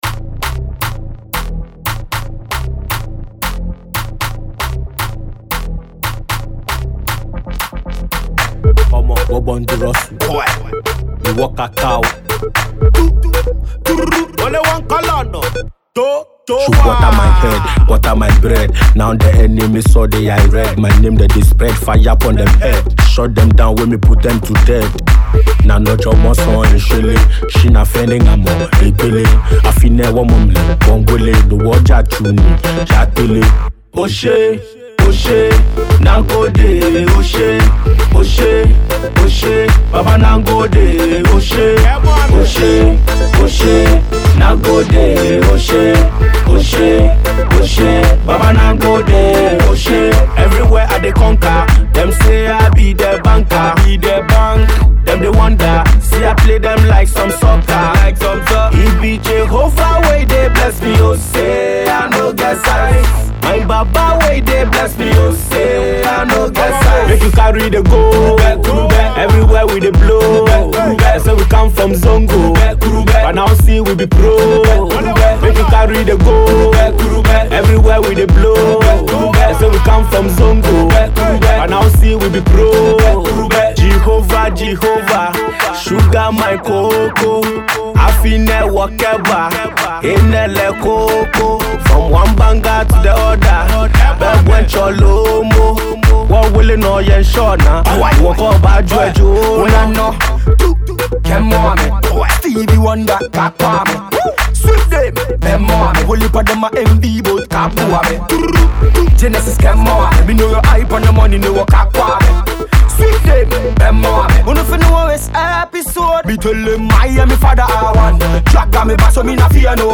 dancehall artist